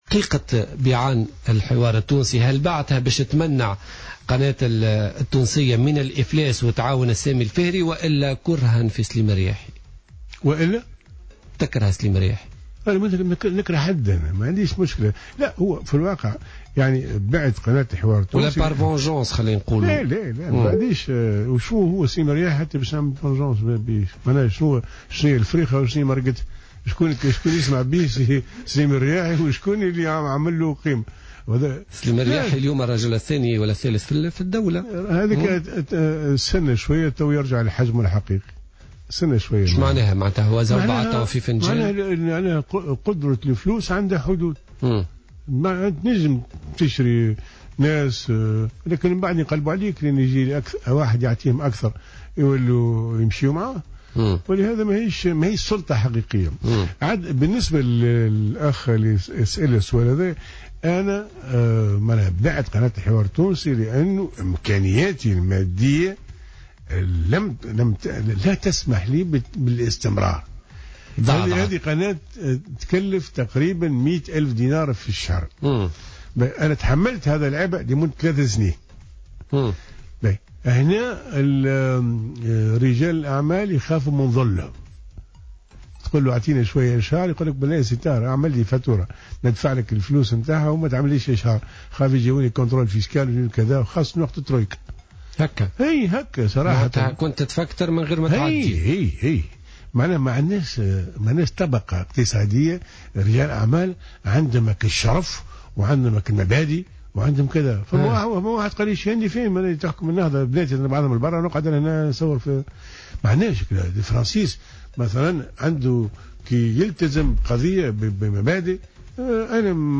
أكد الطاهر بن حسين في تصريح للجوهرة أف أم اليوم الاثنين أن بيعه لقناة الحوار التونسي كان لأسباب مادية بحتة على اعتبار عدم قدرته على توفير قرابة 100 ألف دينار كمصاريف شهرية قارة للقناة.